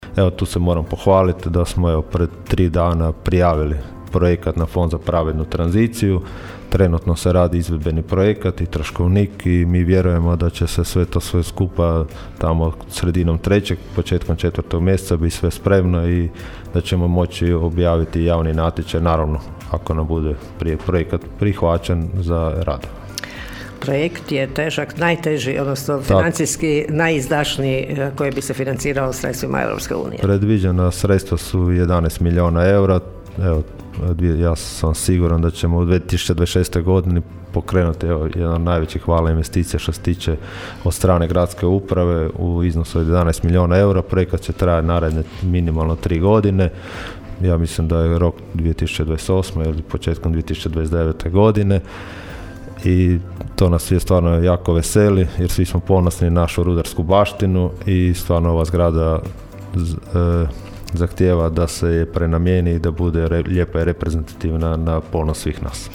Gradonačelnik Labina Donald Blašković u Gradskim minutama govorio je o prvih sedam mjeseci mandata, izazovima u radu i intenzivnim aktivnostima na projektu Velikog kupatila.
ton – Donald Blašković), rekao je gradonačelnik Blašković.